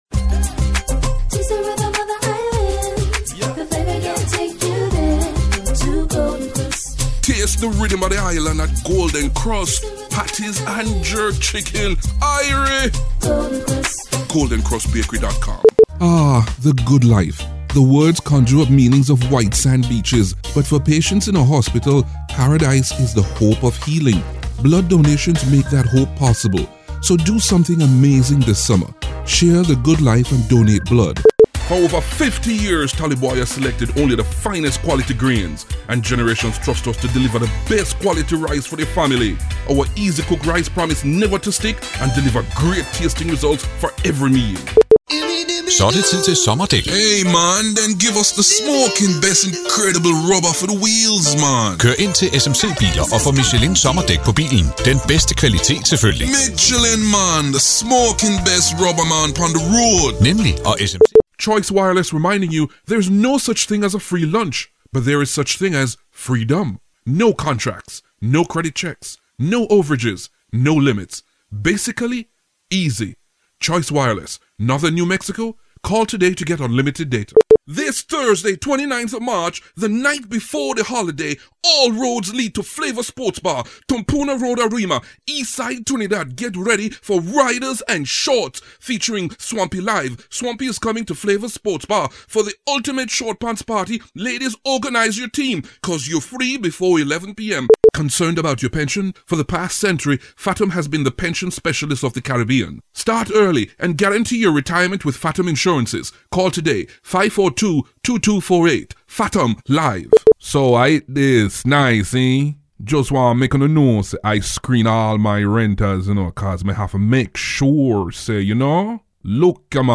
Jamaican native. Some have said: "...good command of the English language...", "...strong commanding voice with mellifluous baritones...", “...excitable, believable and reassuring...", “...very corporate for a professional setting...", "...very smooth and sexy...”
Sprechprobe: Werbung (Muttersprache):